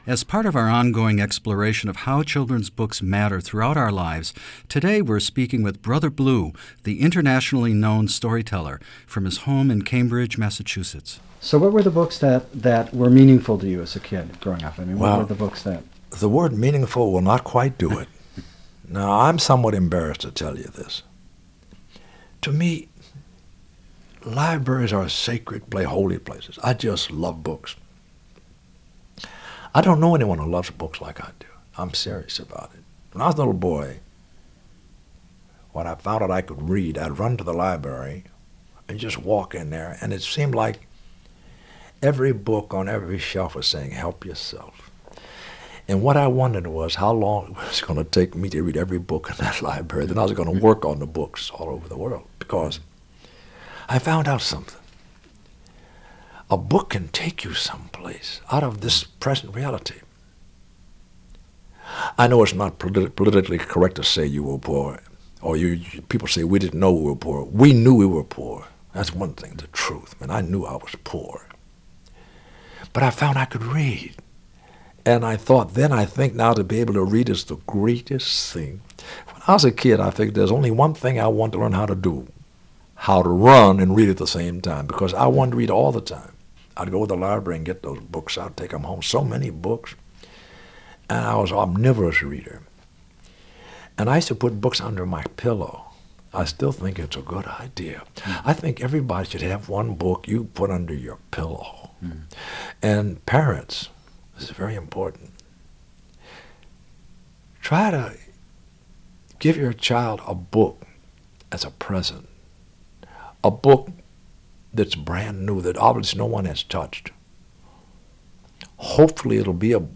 As part of our ongoing exploration of how children's books matter throughout our lives, today we're speaking with Brother Blue, the internationally known storyteller, from his home in Cambridge, Massachusetts.